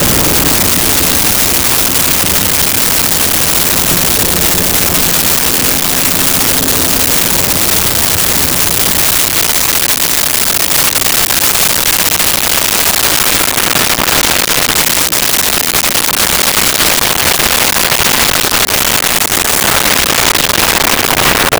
Military Helicopter Idle Away
Military Helicopter Idle Away.wav